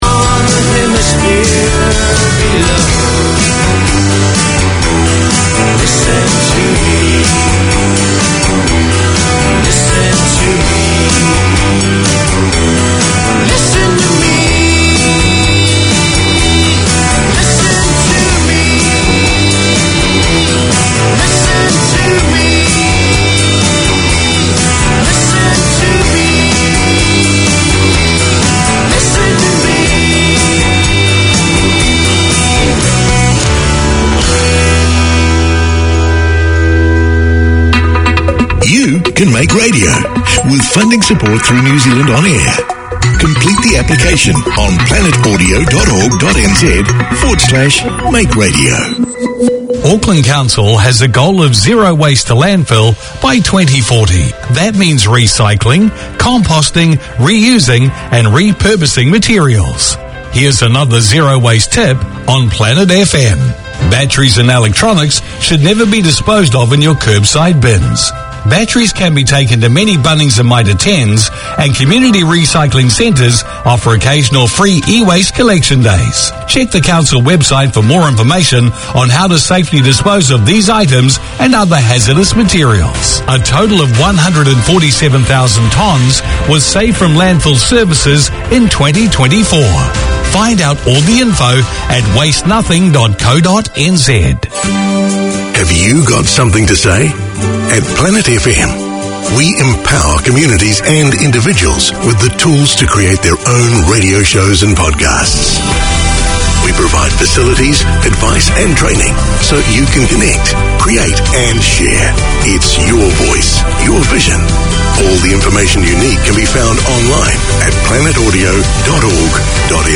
Community Access Radio in your language - available for download five minutes after broadcast.
Pasifika Wire Live is a talanoa/chat show featuring people and topics of interest to Pasifika and the wider community.